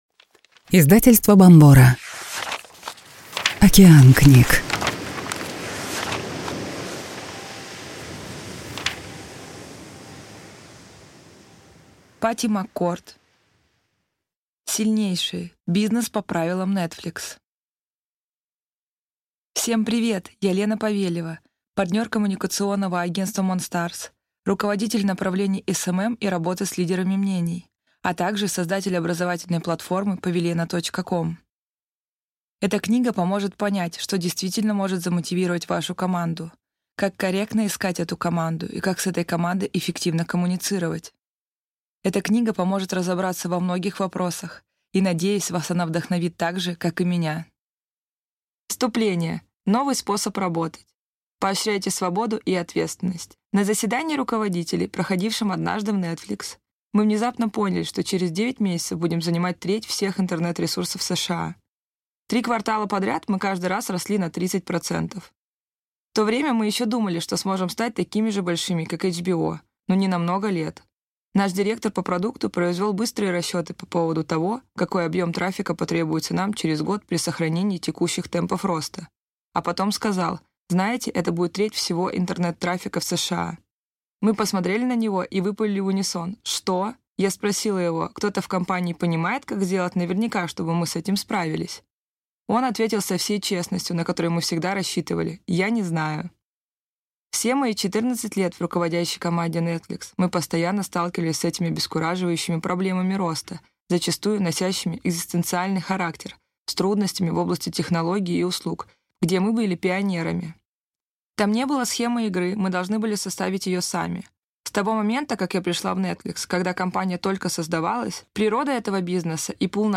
Aудиокнига Сильнейшие.